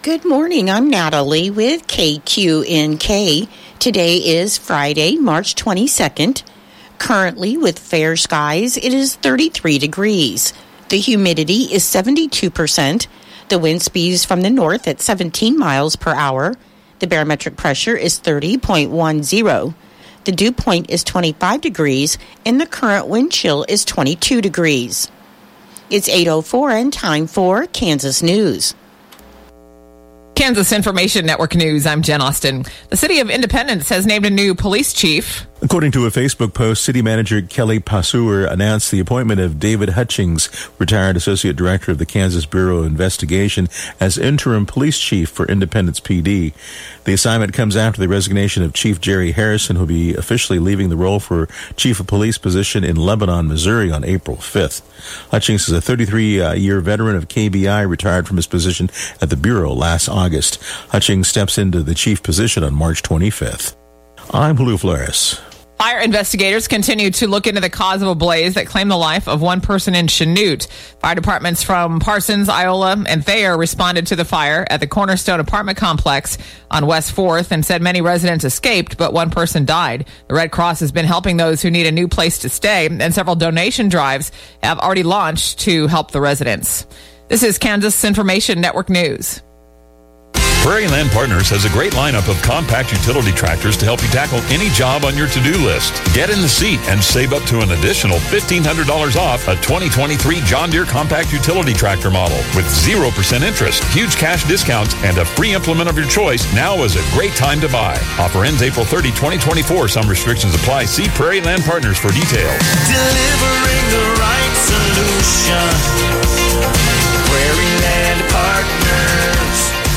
Classic Hits Q-106.7 & 102.5 KQNK News, Weather & Sports Update - 3/22/2024